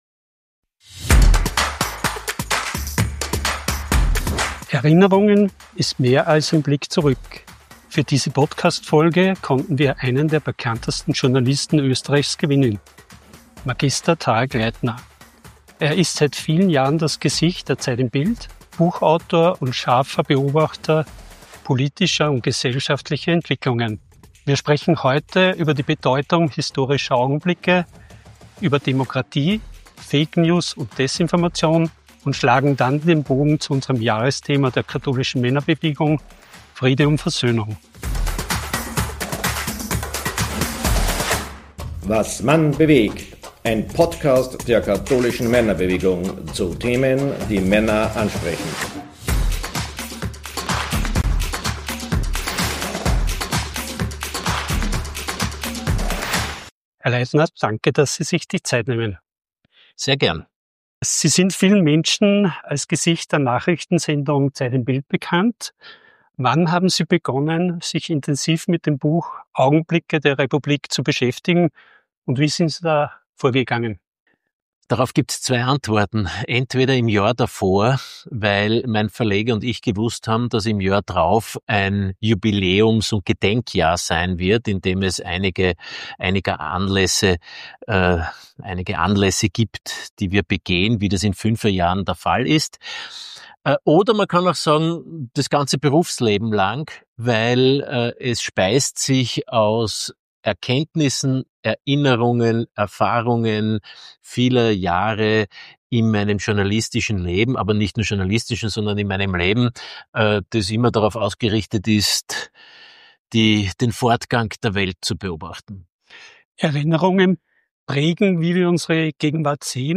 im Gespräch mit ZIB-Moderator Mag. Tarek Leitner